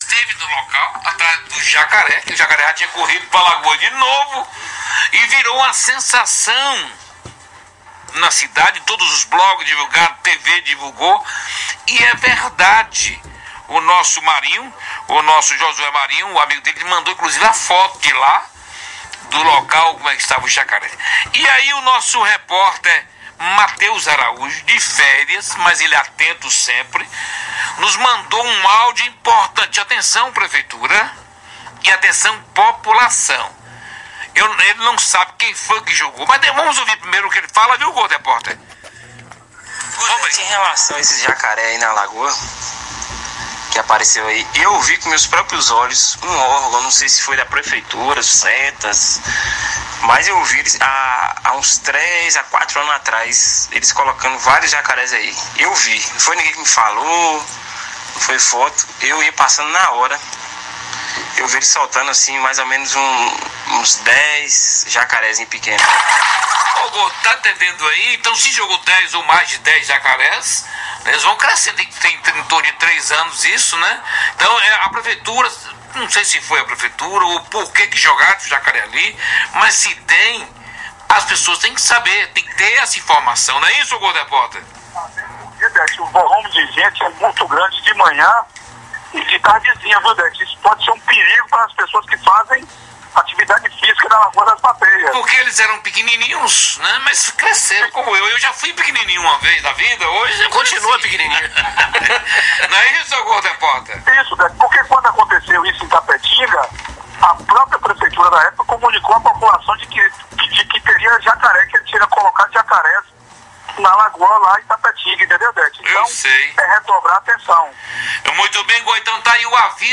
Mais um capítulo da novela envolvendo jacarés em Vitória da Conquista. No programa Redação Brasil de hoje (quinta-feira) foi divulgado que podem existir novos jacarés no entorno da Lagoa das Bateias, o que deixa a população em alerta.